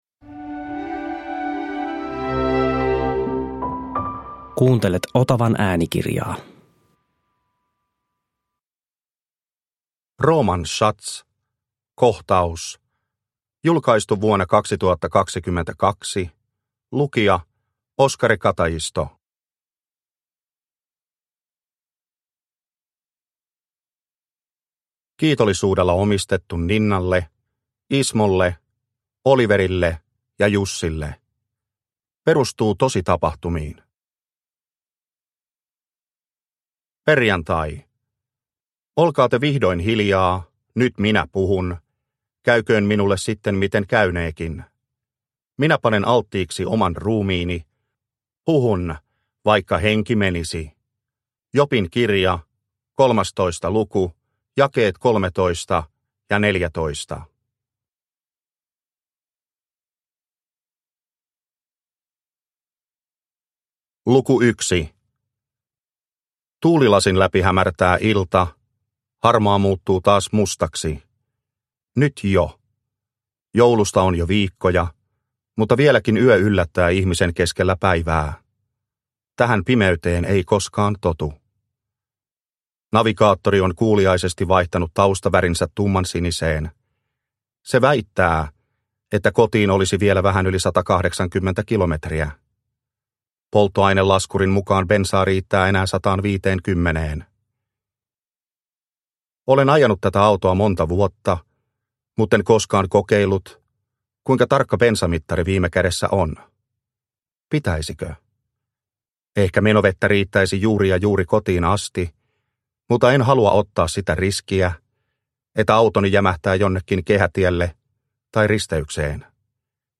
Kohtaus – Ljudbok